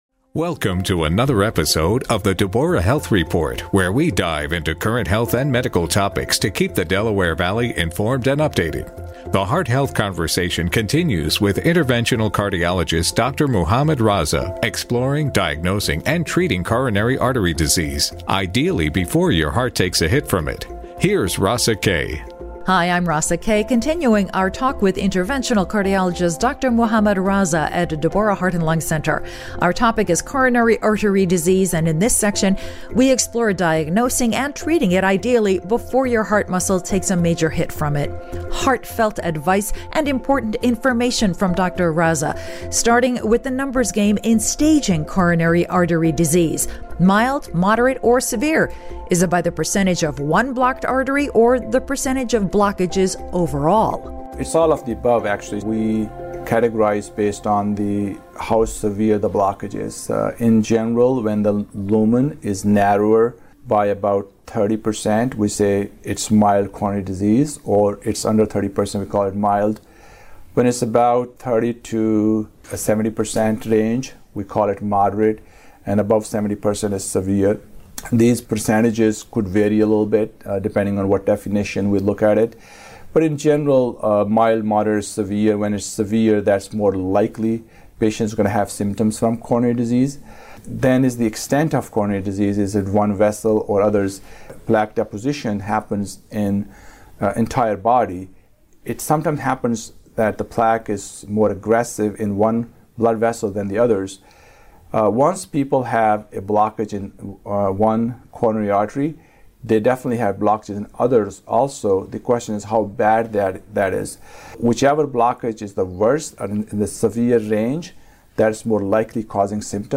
The heart health conversation continues.